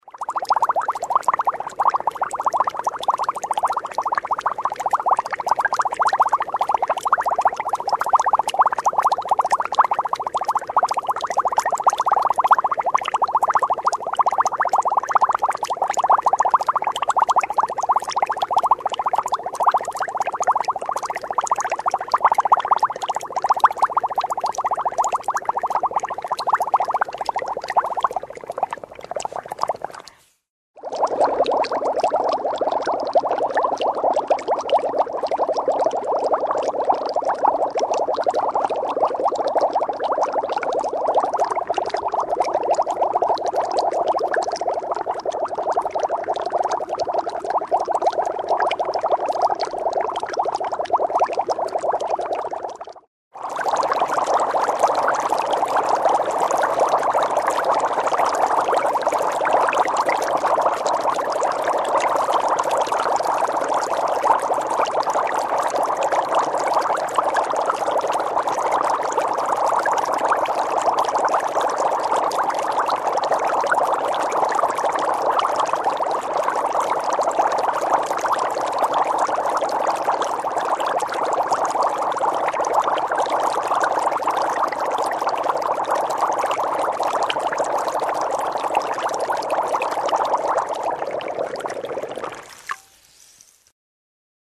Buih air | Muat turun kesan bunyi .mp3.
Buih air: